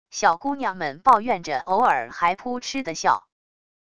小姑娘们抱怨着偶尔还噗哧的笑wav音频